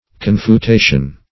Confutation \Con`fu*ta"tion\, n. [L. confutatio: cf. F.